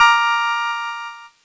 Cheese Chord 16-A#3.wav